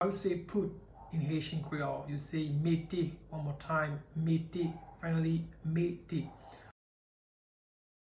Listen to and watch “Mete” pronunciation in Haitian Creole by a native Haitian  in the video below:
Put-in-Haitian-Creole-Mete-pronunciation-by-a-Haitian-teacher.mp3